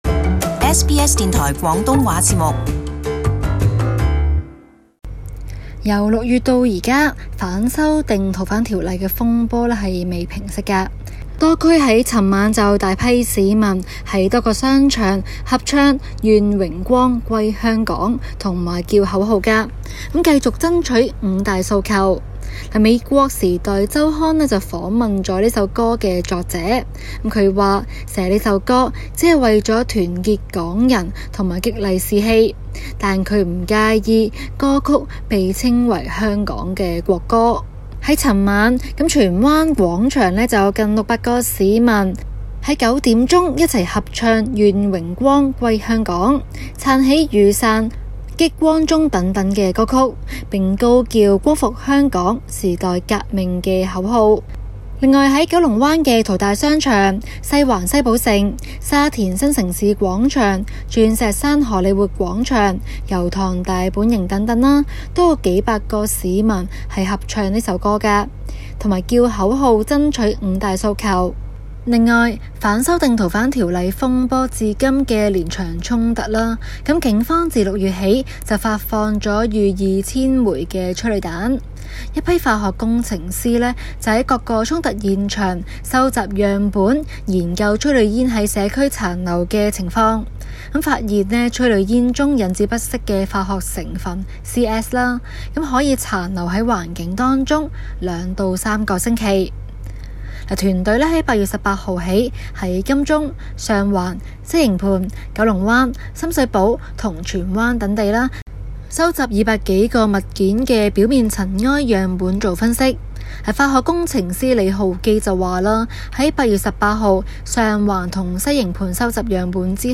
本台駐港通訊員為大家報導。